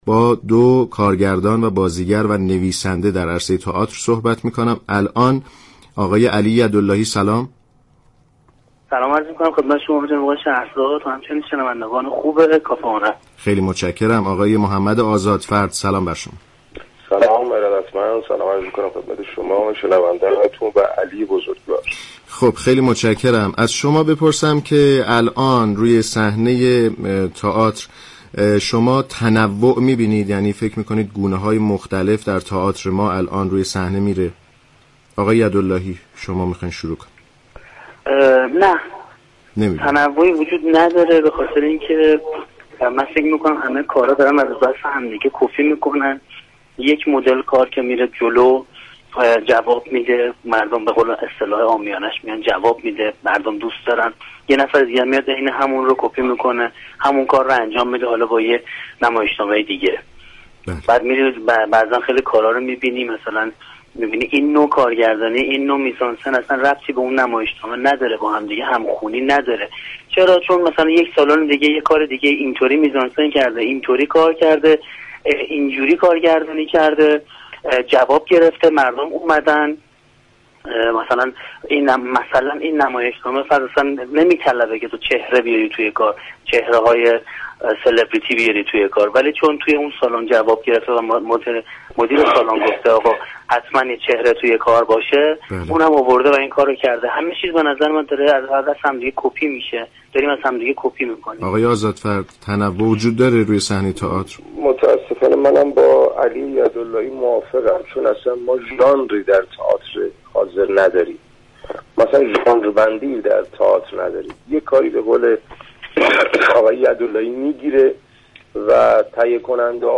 برنامه كافه هنر رادیو ایران در این خصوص و درباره اینكه آیا تنوع در صحنه تئاتر وجود دارد یا خیر با دو كارگردان و نویسنده صحبت كرده است.
این گفت و گو را می شنویم : دریافت فایل منبع سازمانی